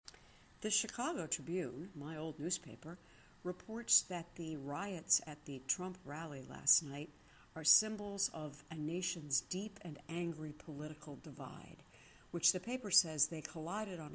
A report from the Chicago Tribune on the Trump demonstration